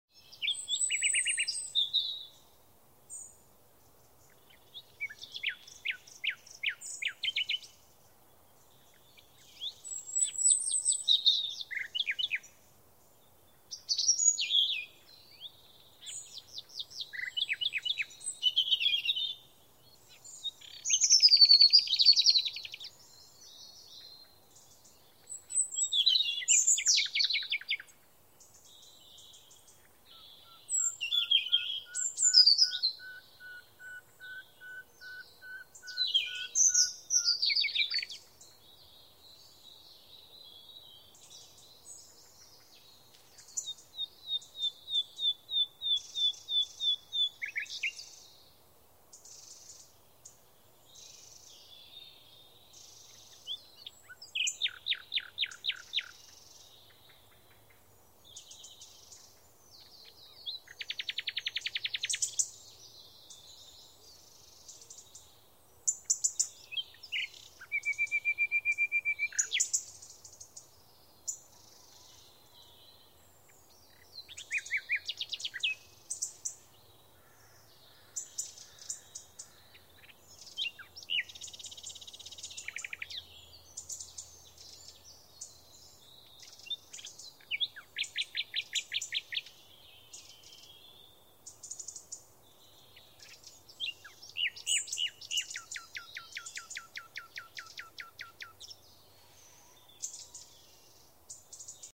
Чарующие звуки птичьего пения в лесу: